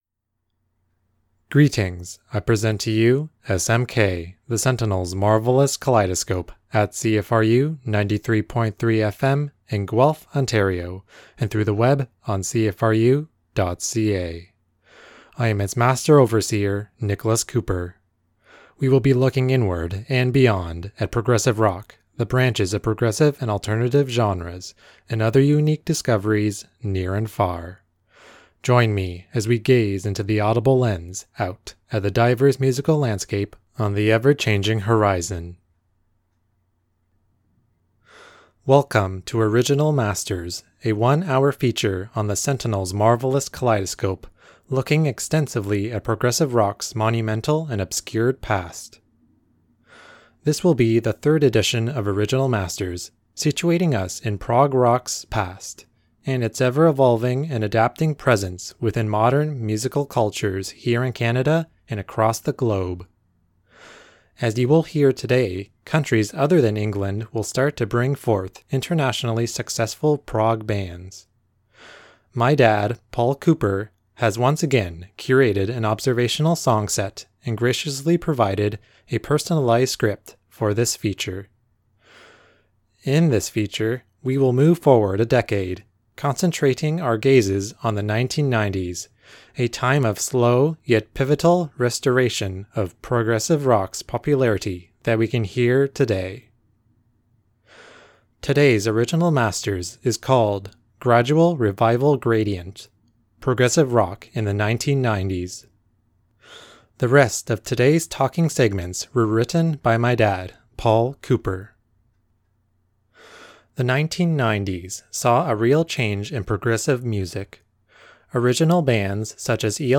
Original Masters 3: Gradual Revival Gradient - Progressive Rock of the 1990s - 09/10/2017